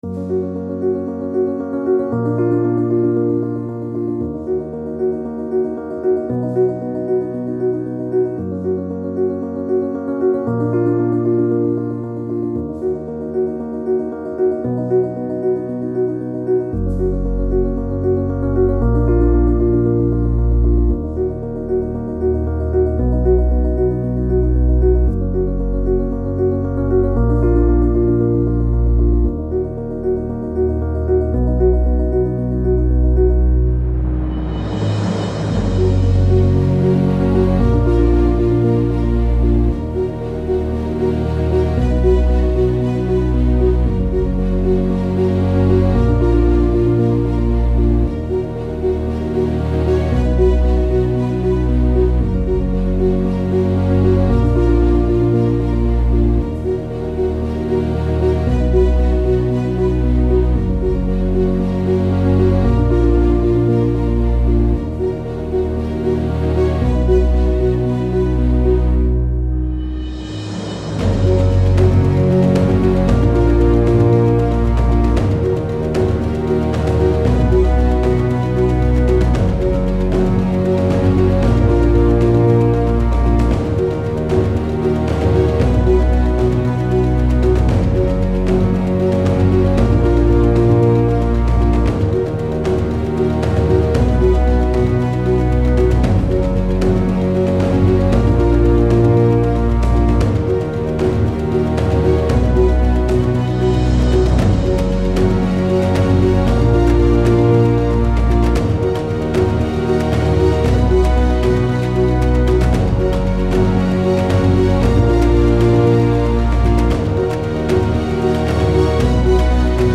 Location: Simi Hills, CA